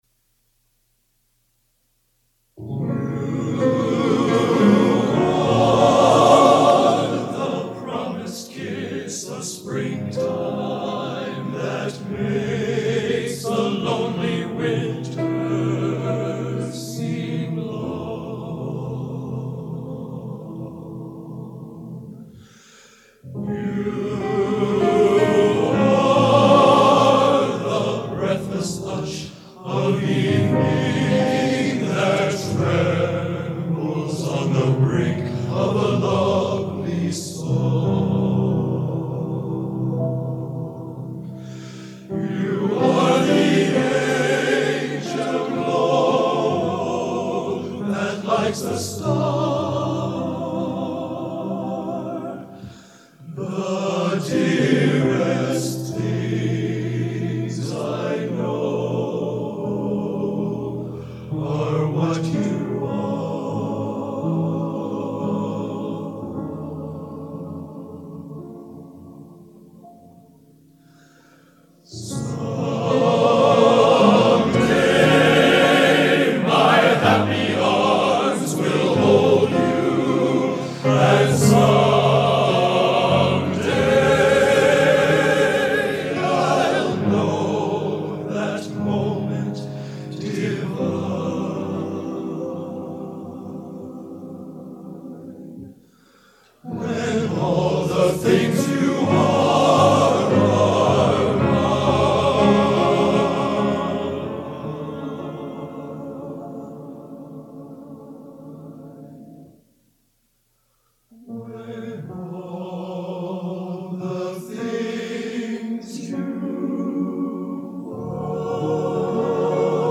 Genre: Popular / Standards Schmalz | Type: Studio Recording